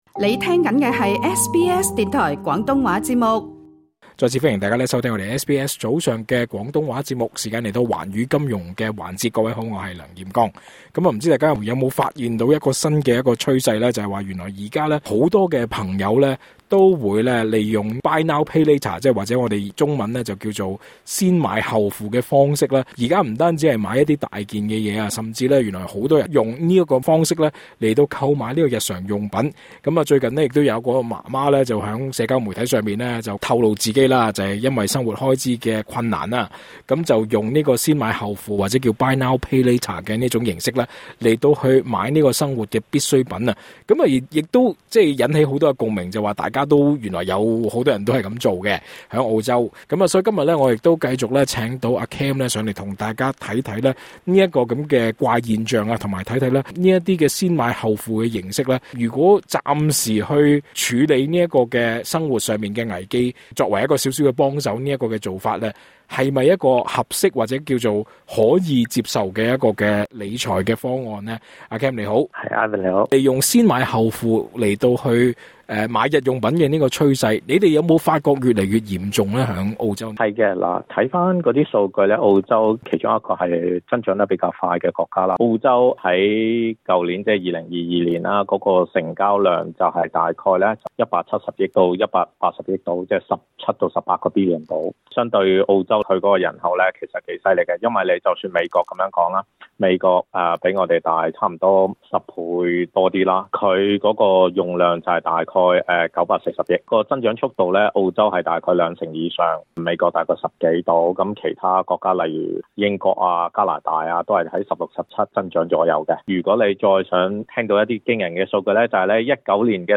更多訪問內容，請留意足本錄音。